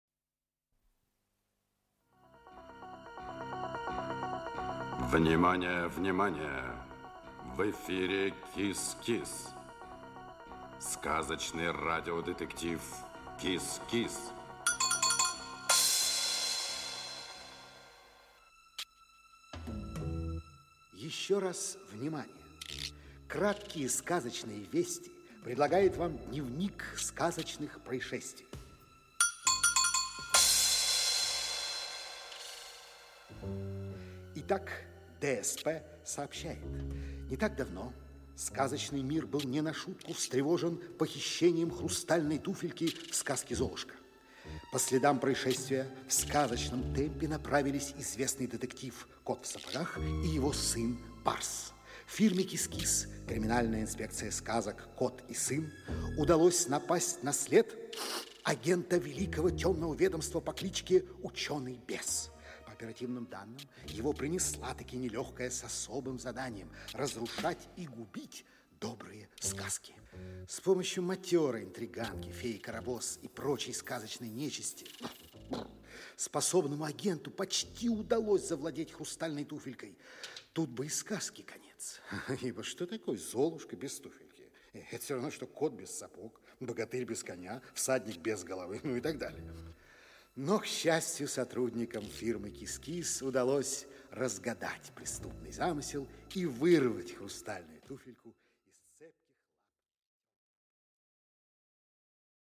Аудиокнига КИС-КИС. Дело № 2. "Операция "Шмель". Часть 1 | Библиотека аудиокниг
Aудиокнига КИС-КИС. Дело № 2. "Операция "Шмель". Часть 1 Автор Зоя Чернышева Читает аудиокнигу Александр Леньков.